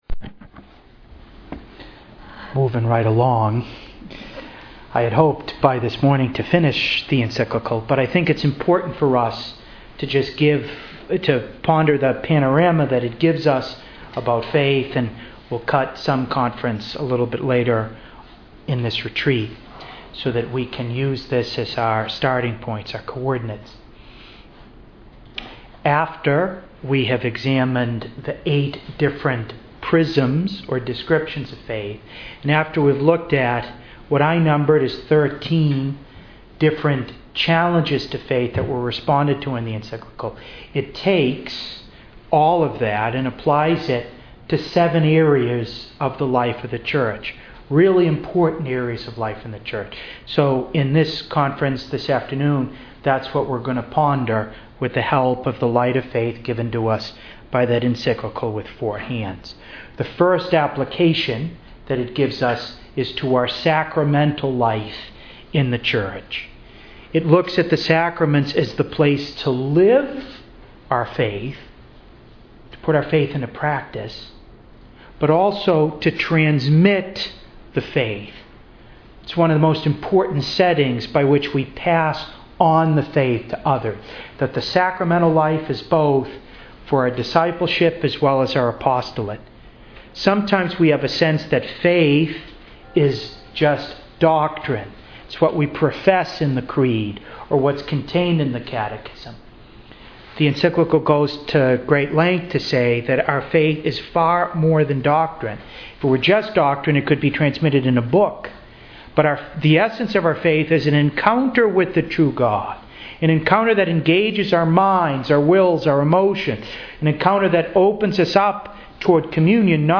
Living by Lumen Fidei (Three Parts), Retreat for the Sisters of Jesus our Hope, July 29 to August 2, 2013 - Catholic Preaching